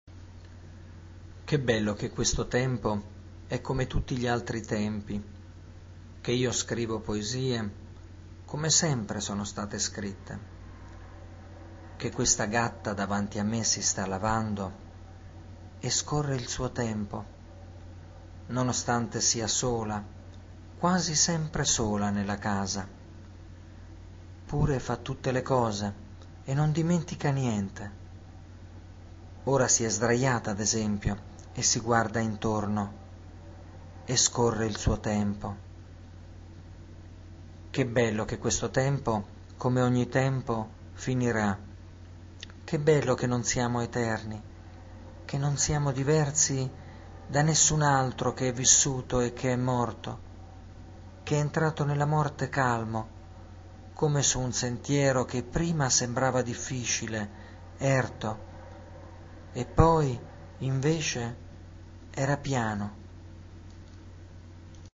[ascolta la poesia letta dall'autore]